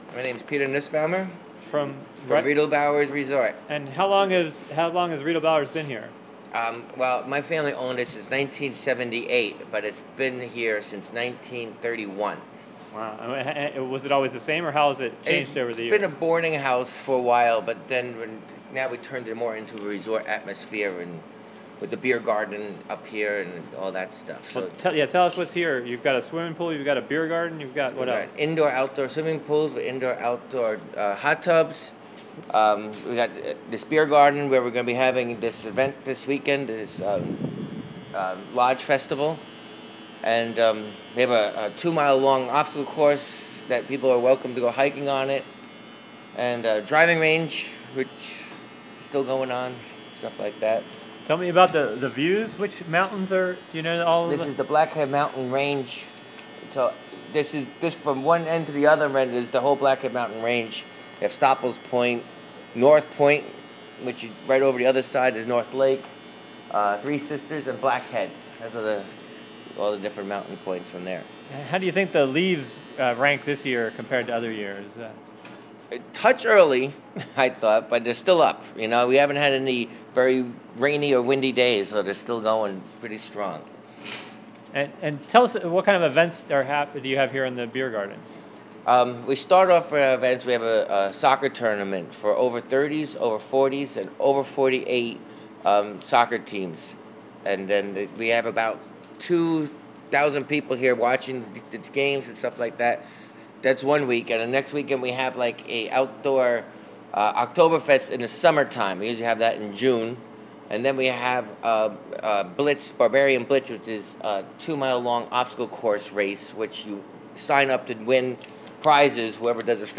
Local interviews, local news, regional events, mus...